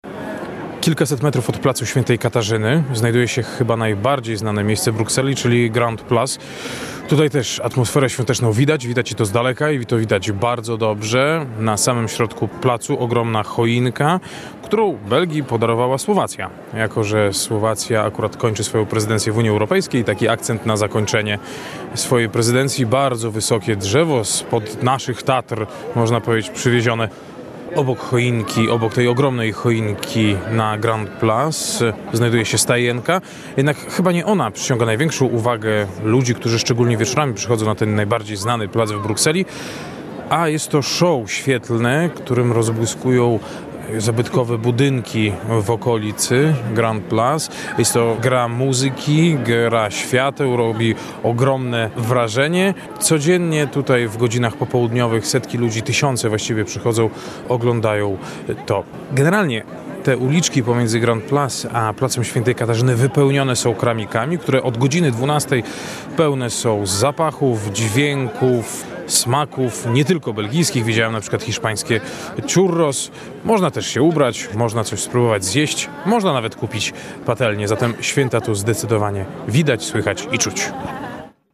Świąteczny okres w Brukseli cz.2 - relacja